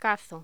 Locución: Cazo
voz